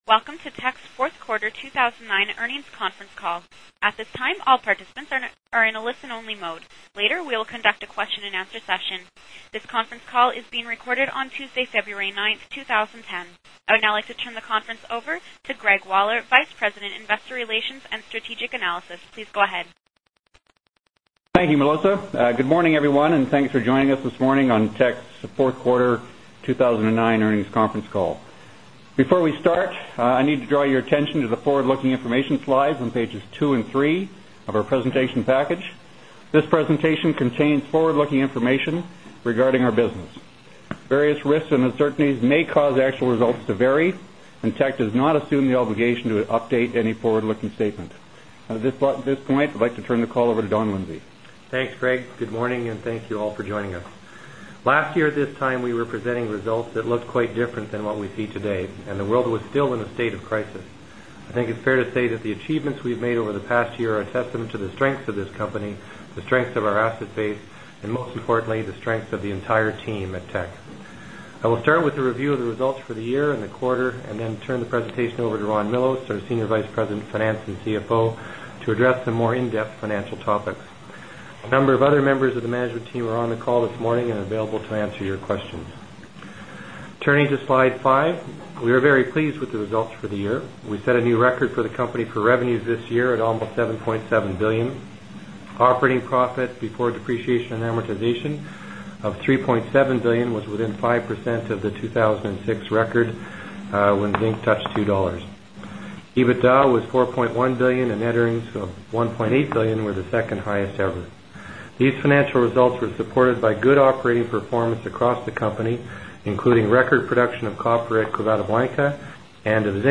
Q4 2009 Financial Report Conference Call